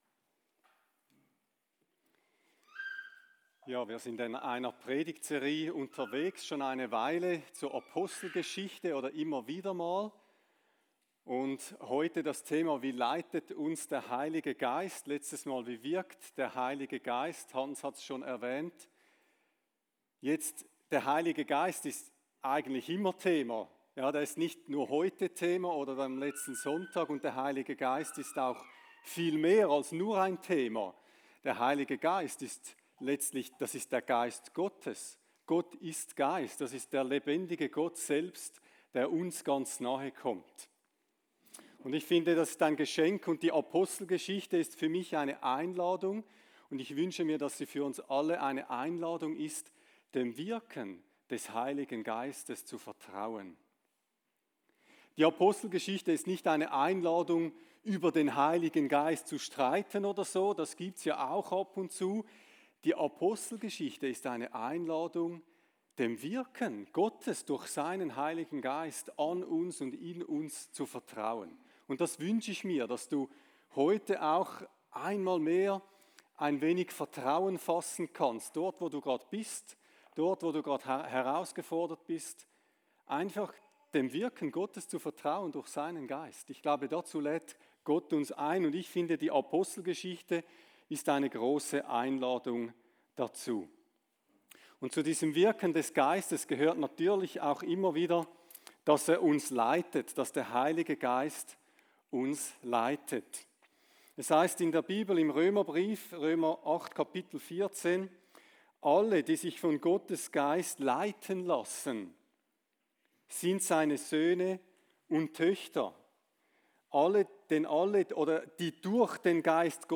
Predigt-20.7.25.mp3